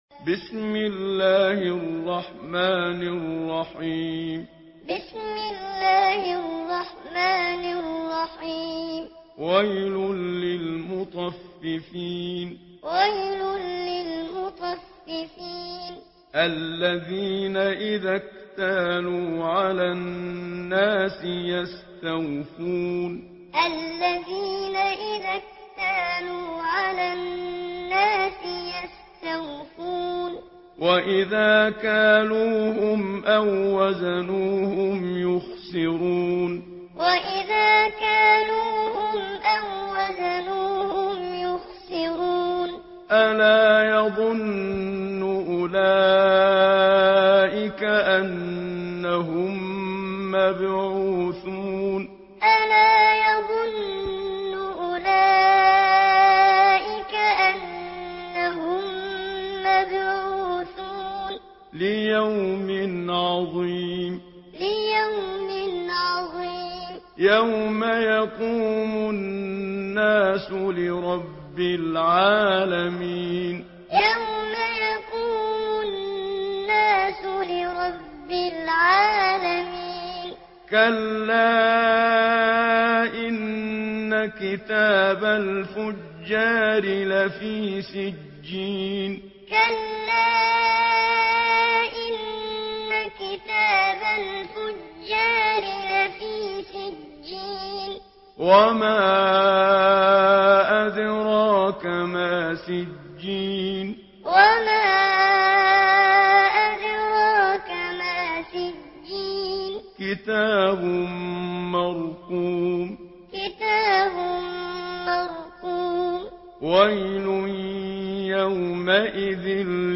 سورة المطففين MP3 بصوت محمد صديق المنشاوي معلم برواية حفص